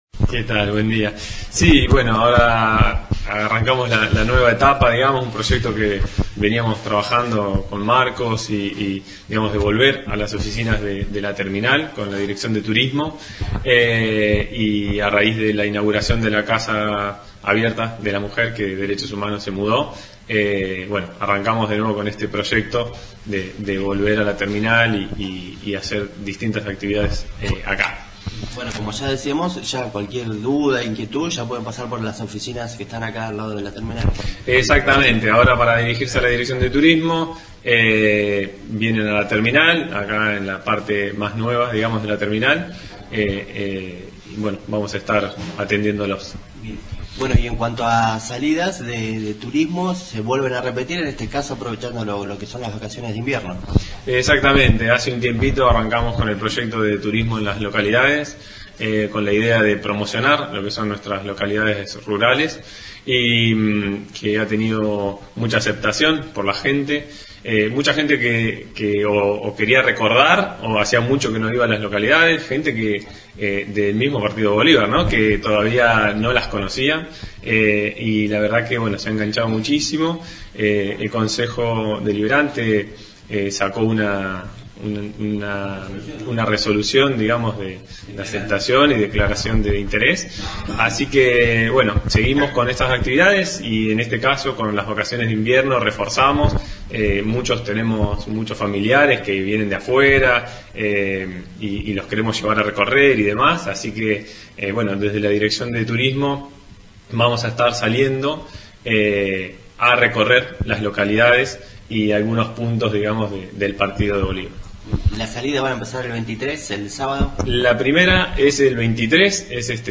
Emilio Leonetti Director de Turismo